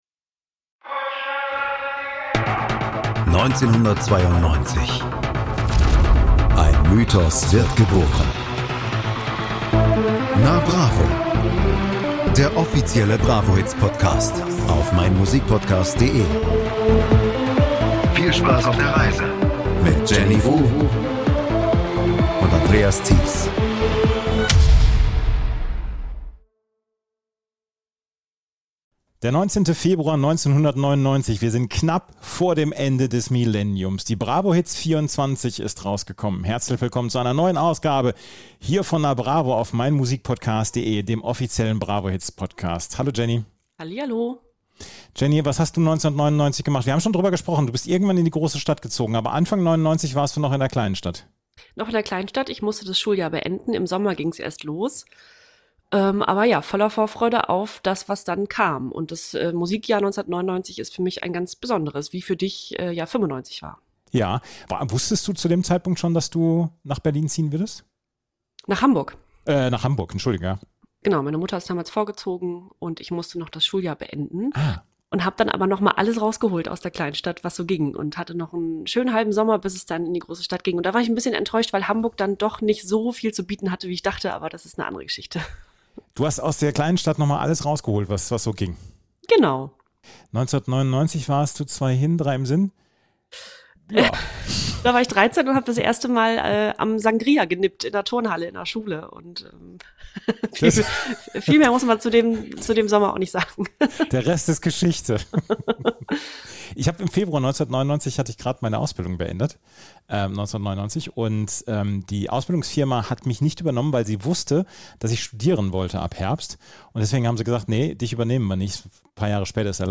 Sie stellen jeweils acht Songs von jeder CD vor, die werden dann aber auch alle angespielt.